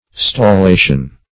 Stallation \Stal*la"tion\ (st[o^]l*l[=a]"sh[u^]n), n.